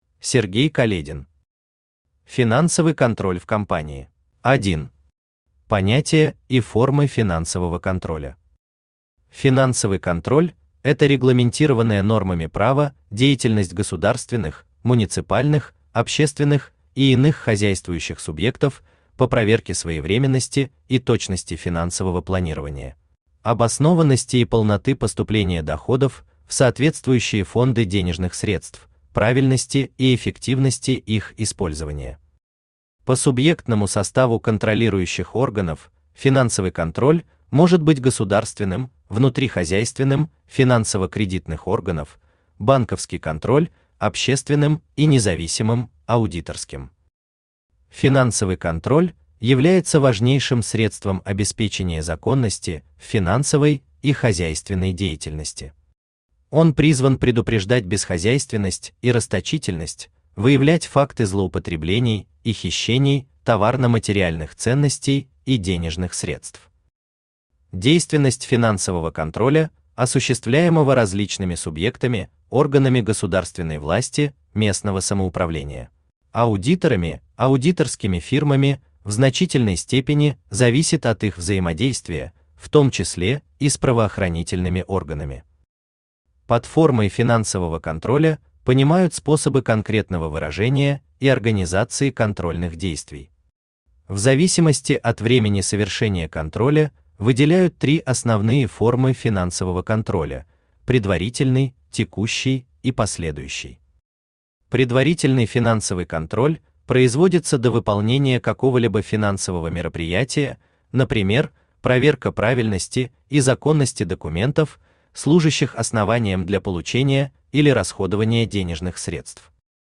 Аудиокнига Финансовый контроль в компании | Библиотека аудиокниг
Aудиокнига Финансовый контроль в компании Автор Сергей Каледин Читает аудиокнигу Авточтец ЛитРес.